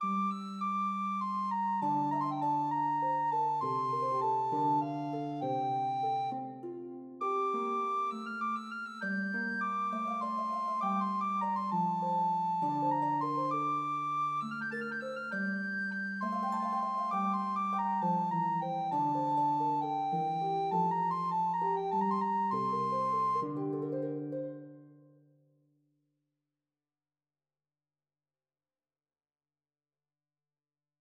리처드 호핀에 따르면, "이 비를레는 자유롭고 텍스트가 없는 테너 위에 두 개의 카논식 성부를 가지고 있다."